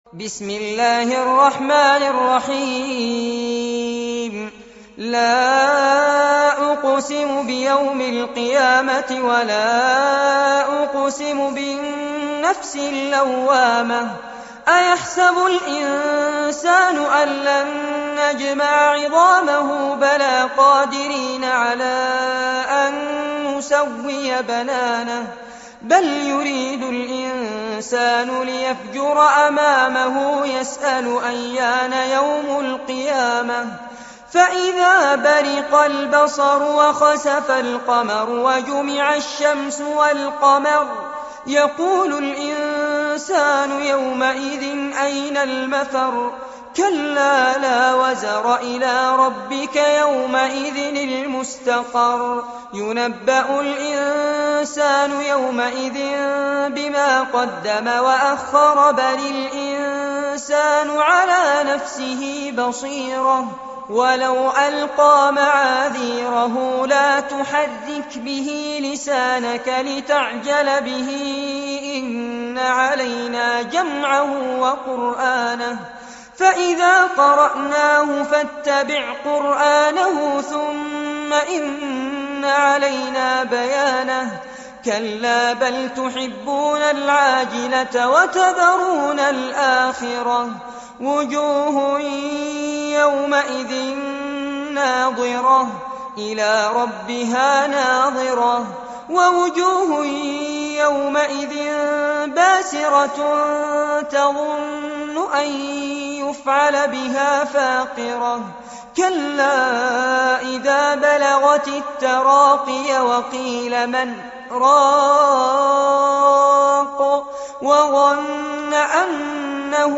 عنوان المادة سورة القيامة- المصحف المرتل كاملاً لفضيلة الشيخ فارس عباد جودة عالية